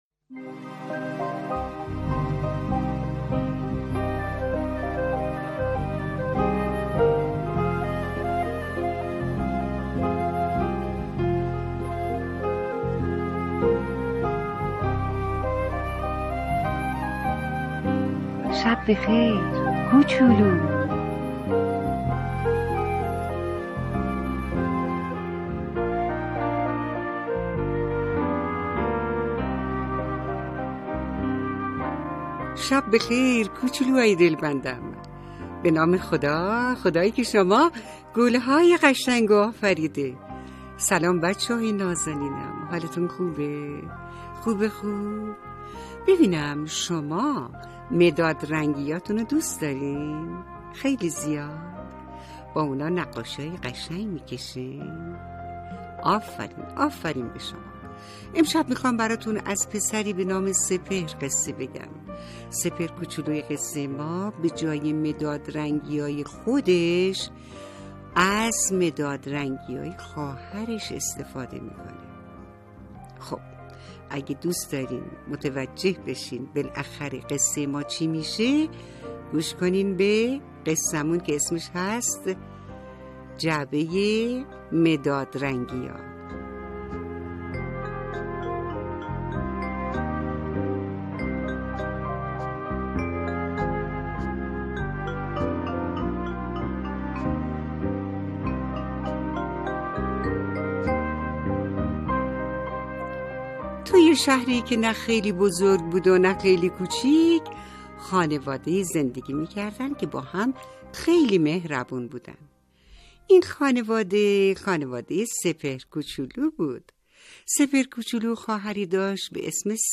قصه صوتی کودکان دیدگاه شما 1,959 بازدید